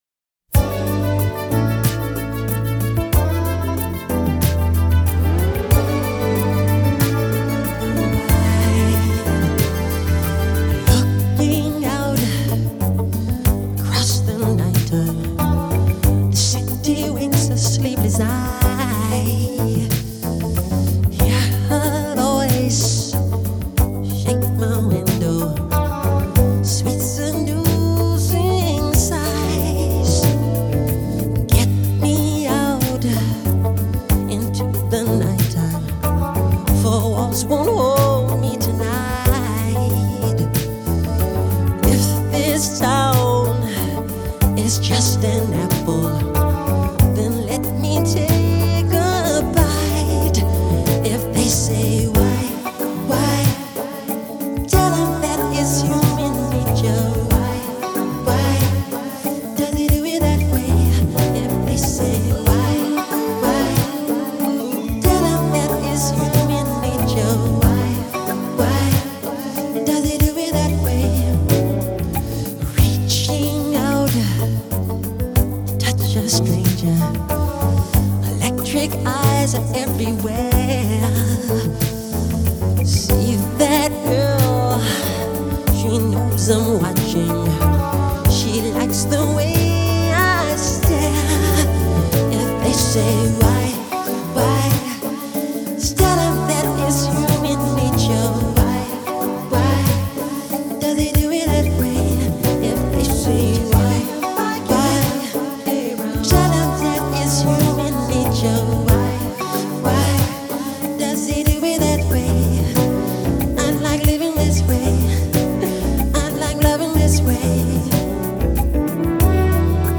Grandes voces en directo y grandes conciertos de la historia
Live at Wembley Stadium July 1988